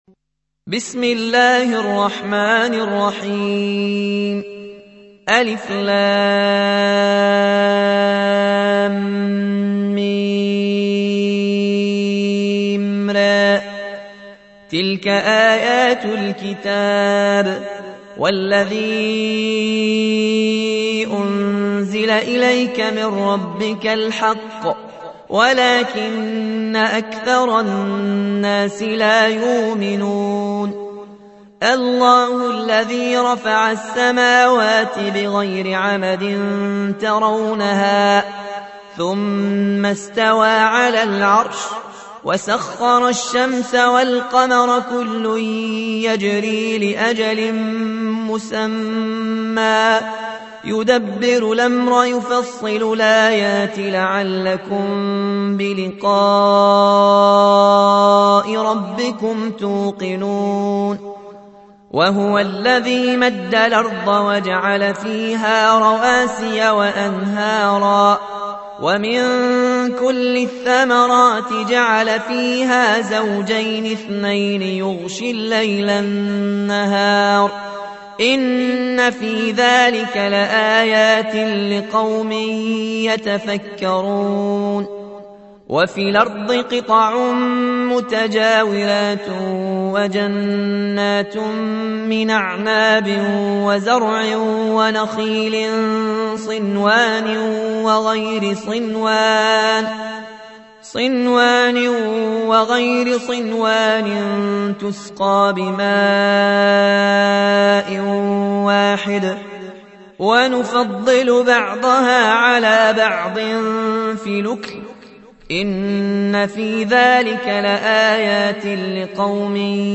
سورة الرعد / القارئ